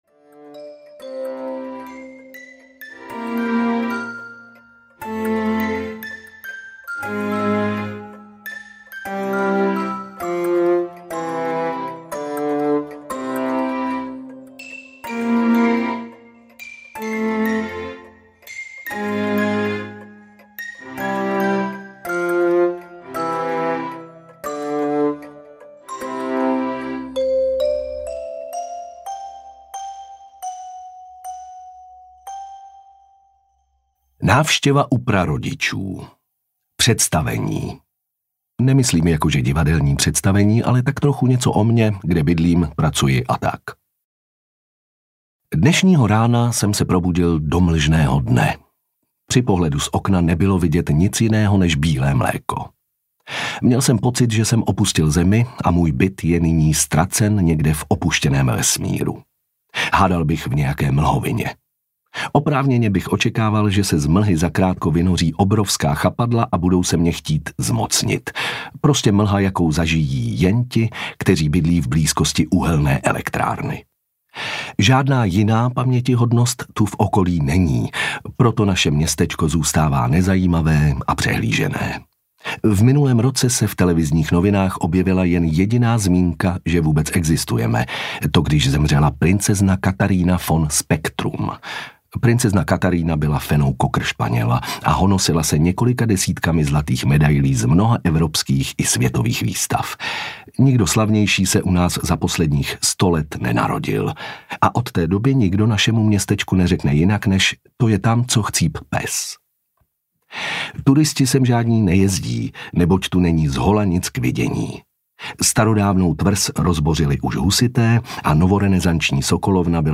Případ ztracené kočky audiokniha
Ukázka z knihy